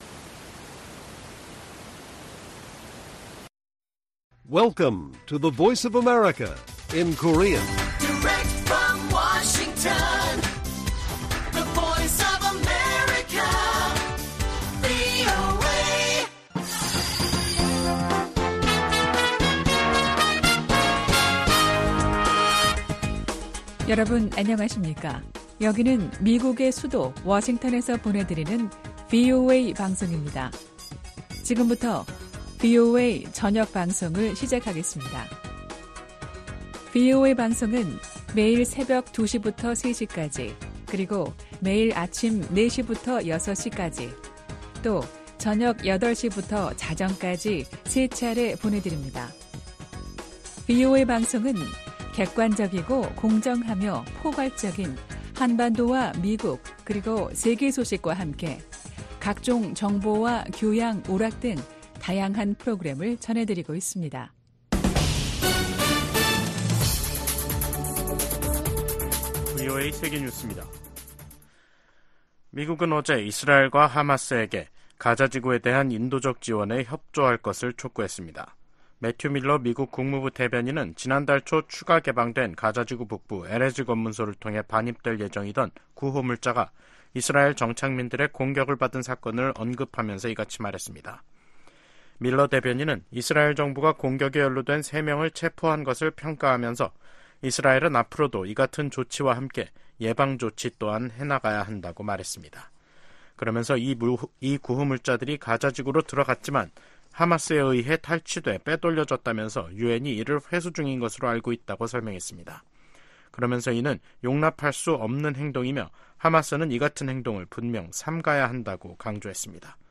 VOA 한국어 간판 뉴스 프로그램 '뉴스 투데이', 2024년 5월 3일 1부 방송입니다. 러시아가 올들어 지금까지 유엔 안보리가 정한 연간 한도를 넘는 정제유를 북한에 공급했다고 백악관이 밝혔습니다. 북한 해커들이 대북 정책 전략이나 관련 정보를 수집하기 위해 미 정부 당국자나 전문가에게 위장 이메일을 발송하고 있어 주의해야 한다고 미국 정부가 경고했습니다.